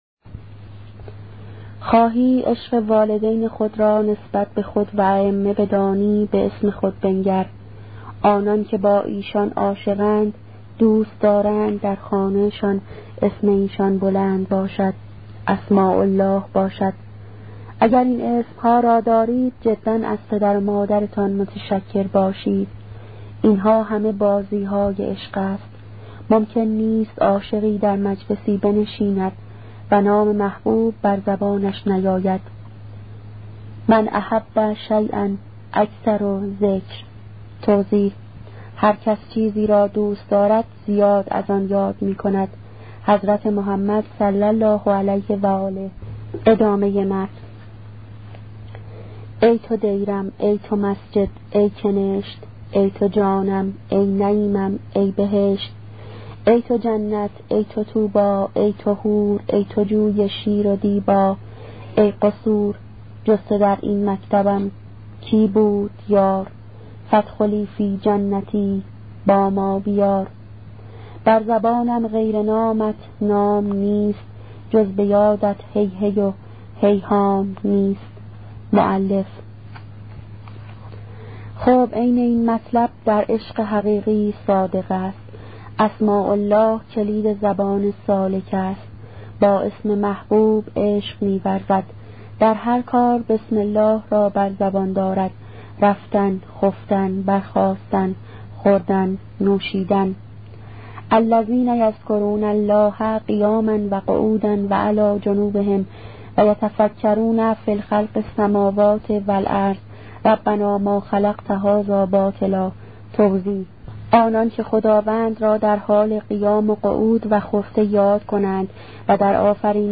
کتاب صوتی عبادت عاشقانه , قسمت نهم